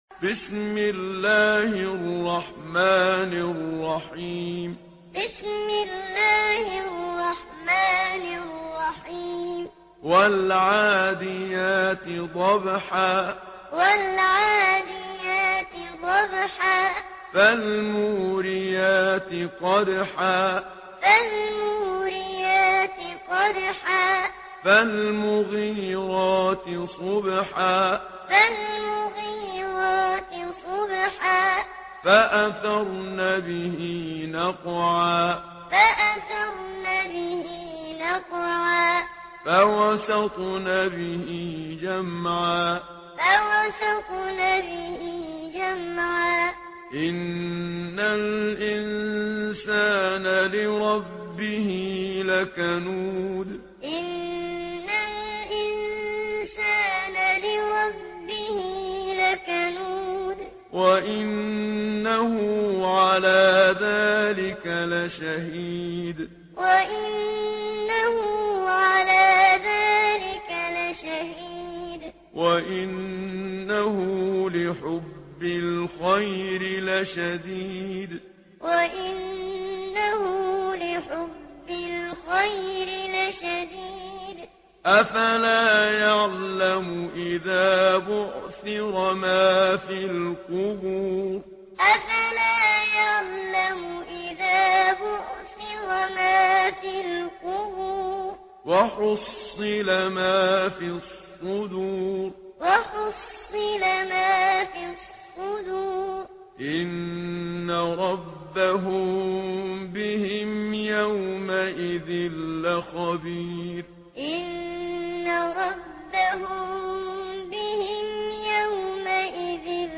سورة العاديات MP3 بصوت محمد صديق المنشاوي معلم برواية حفص
تحميل سورة العاديات بصوت محمد صديق المنشاوي